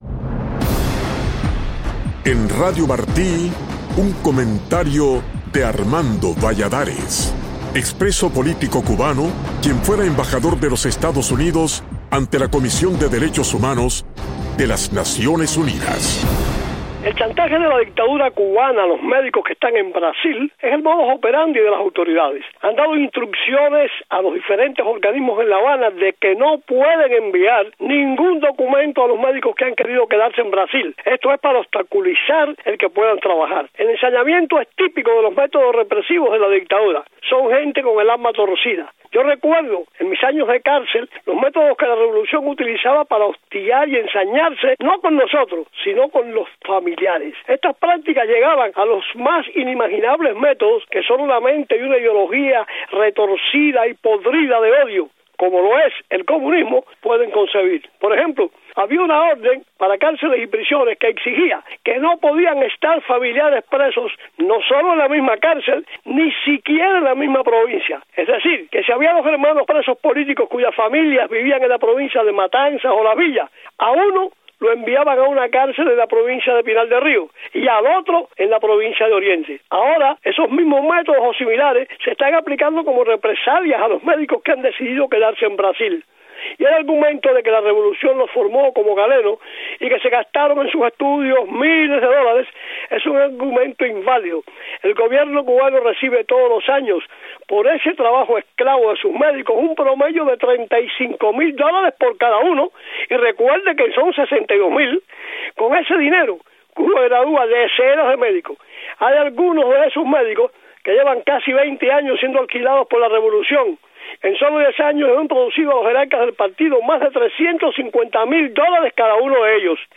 Embajador Valladares: Mi opinión